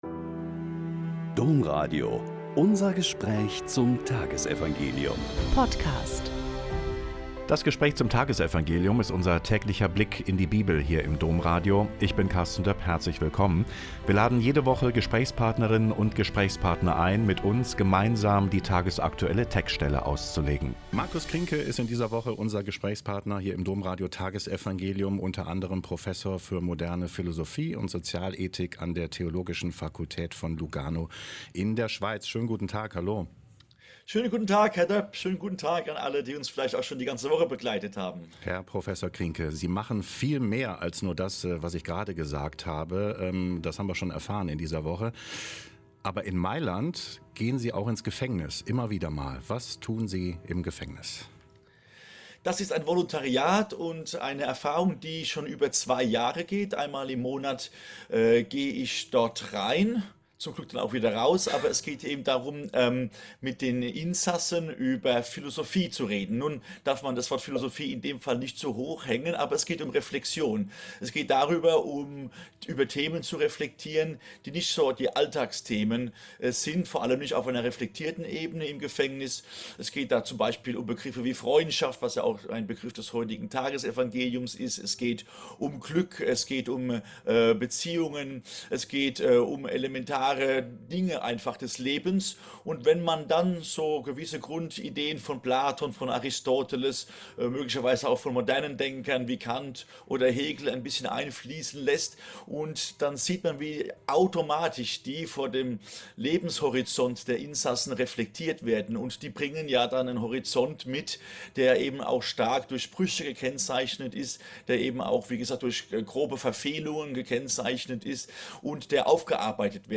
Joh 15,12-17 - Gespräch